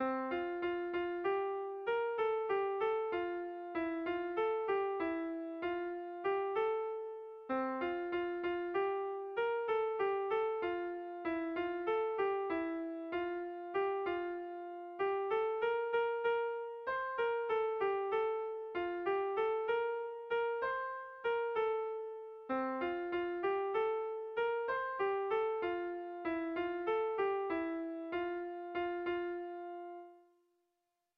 Air de bertsos - Voir fiche   Pour savoir plus sur cette section
A1A1BA2